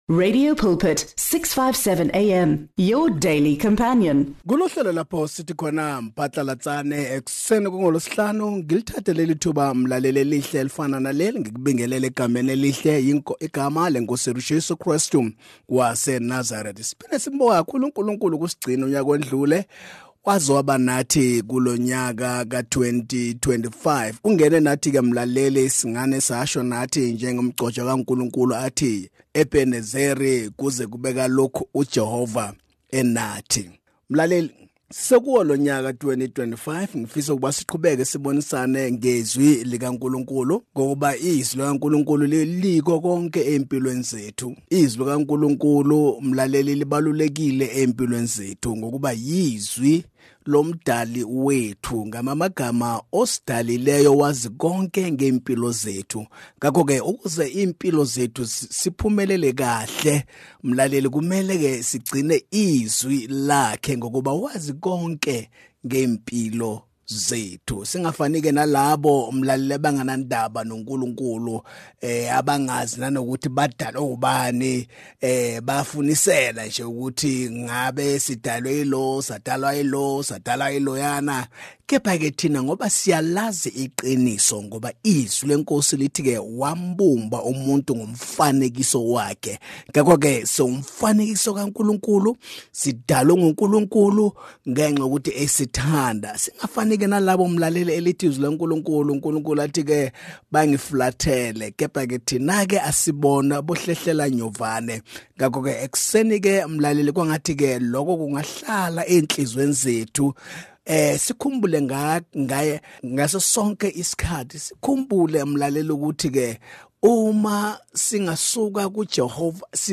Listeners can expect a fresh Word from God early in the morning every weekday. Pastors from different denominations join us to teach the Word of God.